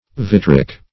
vitric - definition of vitric - synonyms, pronunciation, spelling from Free Dictionary
Search Result for " vitric" : The Collaborative International Dictionary of English v.0.48: Vitric \Vit"ric\, a. [L. vitrum glass.]